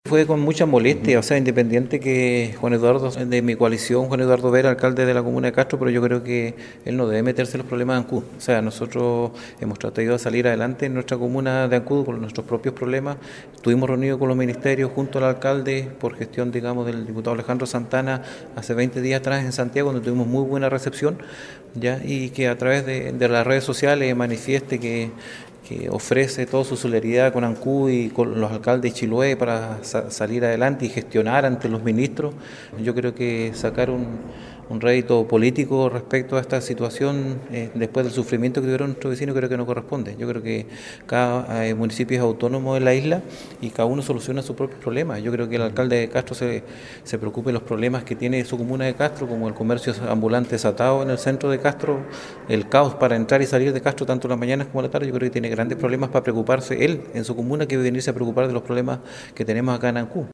El concejal Alex Muñoz se manifestó muy molesto por la actitud del alcalde de Castro, y señaló que en su territorio tiene ya bastantes problemas que atender, para que tenga que entrometerse en los asuntos de Ancud.